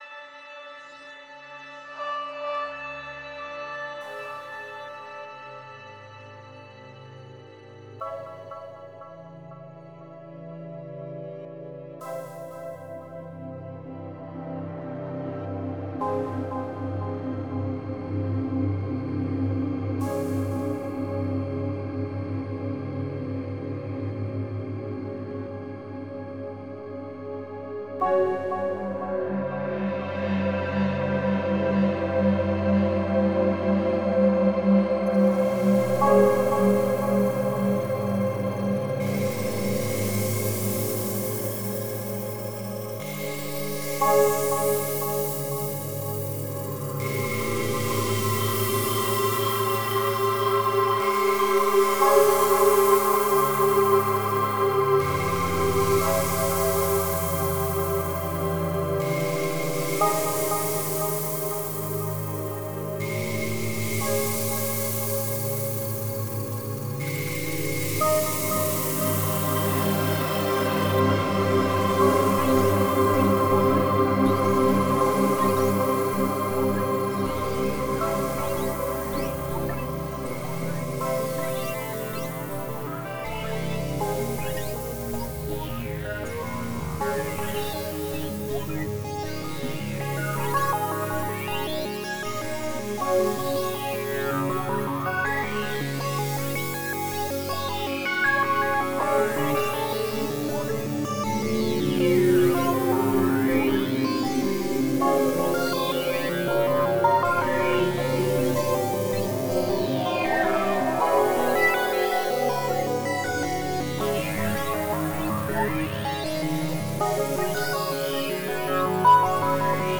Soundscapes and images inspired (at least in part) by avant garde composers of the last century, including Karheinz Stockhausen and György Ligeti.